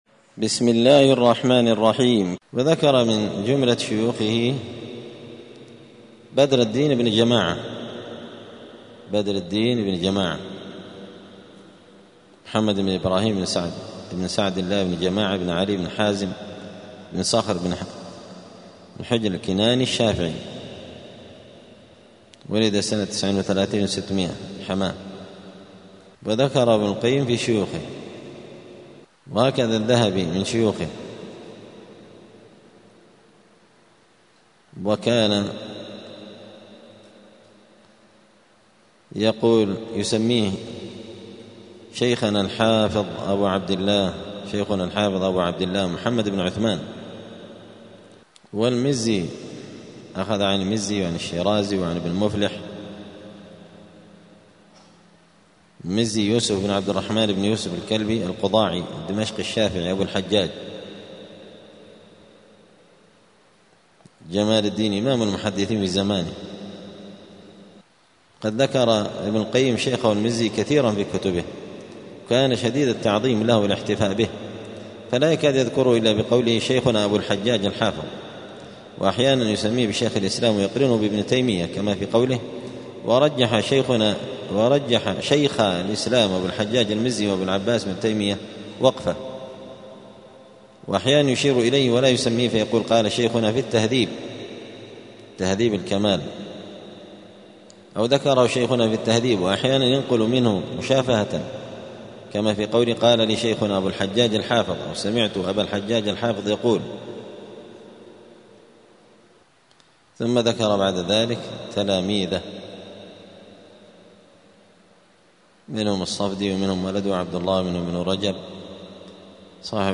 دار الحديث السلفية بمسجد الفرقان قشن المهرة اليمن
الدروس اليومية